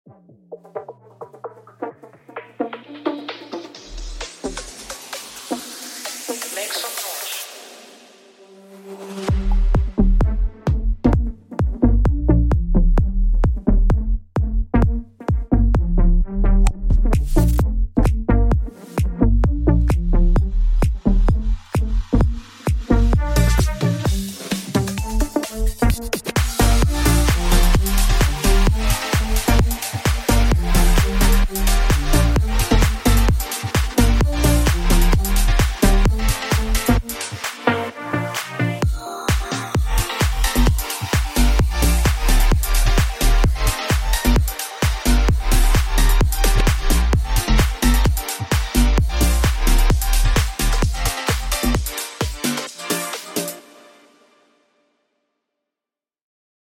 ויש תדרים גבוהים שצורמים קצת על כל העסק…...
HOUSE.wav מבחינת המיקס זה ממש השלב ההתחלתי אבל אהבתי את העומק אגב אתם לא מאמינים איזה מקבצים אני בונה על הGENOS עם הסט החדש שלי אתם נופלים מהרגליים ברוך ה’ זה מתקדם יפה